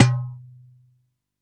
Hand Darbuka 01.wav